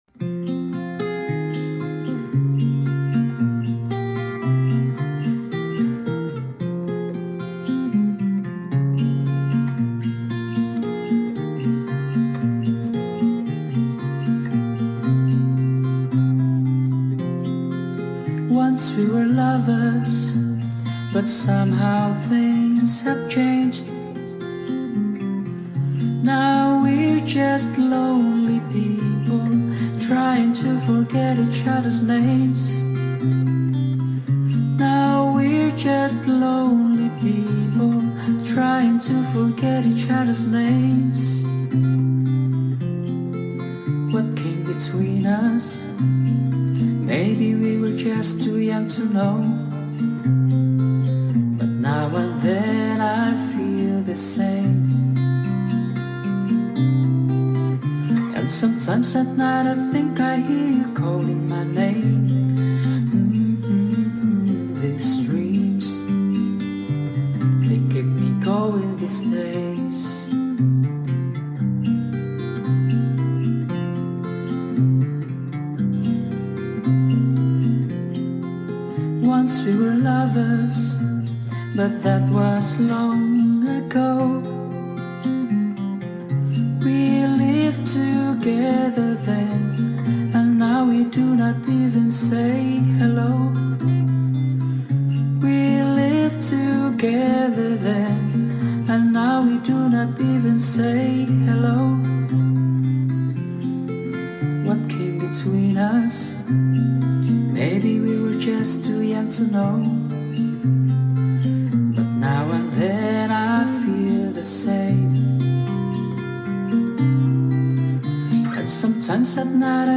is a poignant ballad about a past love that has faded away.